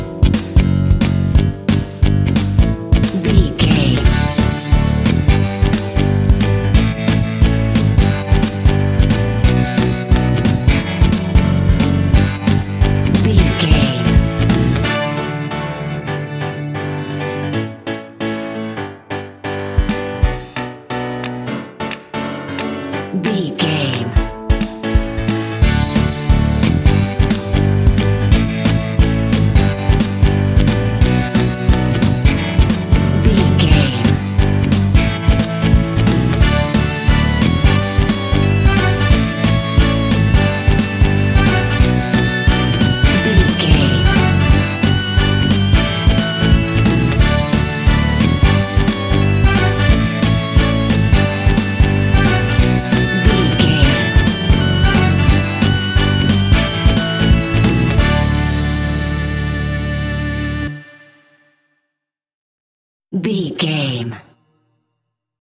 Uplifting
Ionian/Major
D
pop rock
indie pop
fun
energetic
acoustic guitars
drums
bass guitar
electric guitar
piano
organ